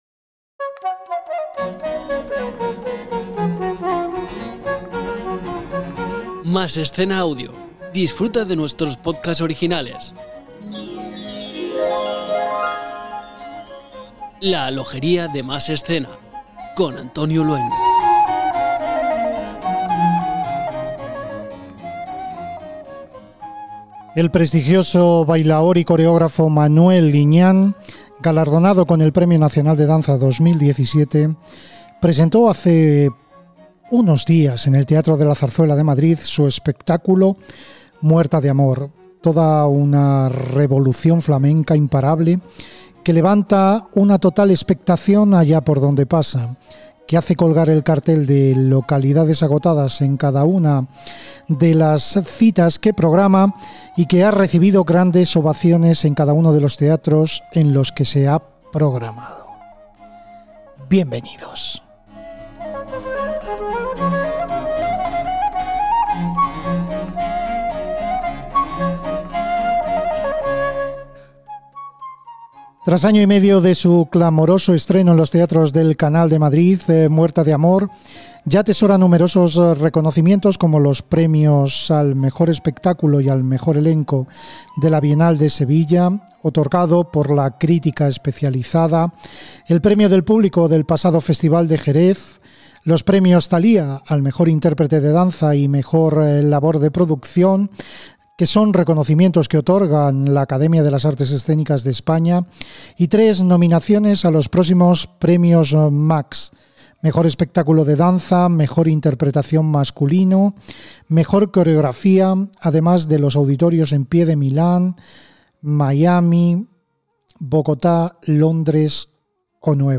Entrevista al bailaor y coreógrafo Manuel Liñán - Masescena